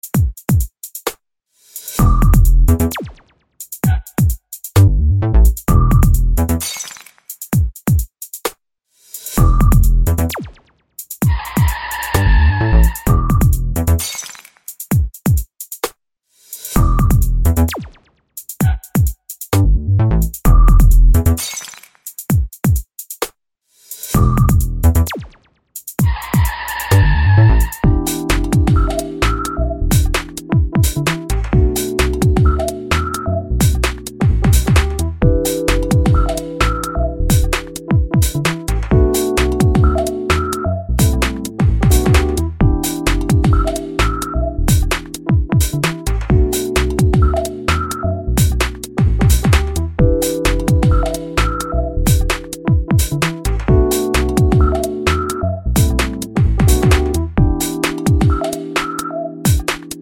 no Backing Vocals with Intro Bar Pop (2000s) 4:07 Buy £1.50